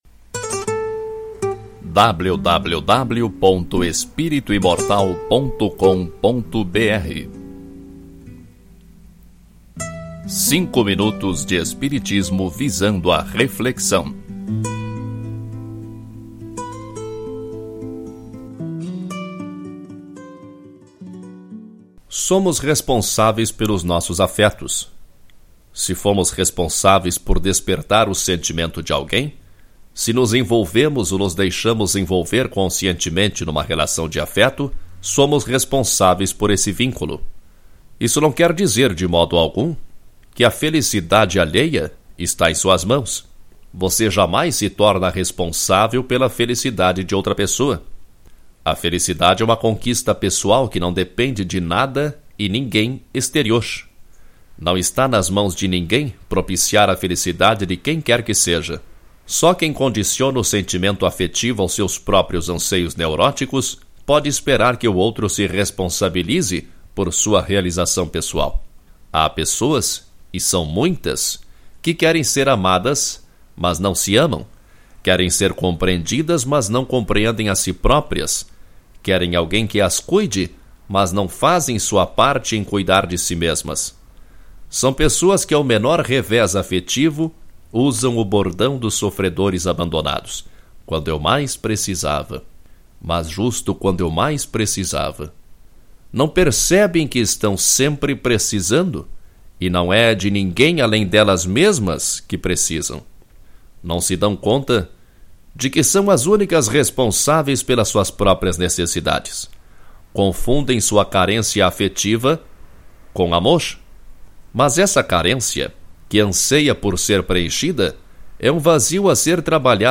Ouça este artigo na voz do autor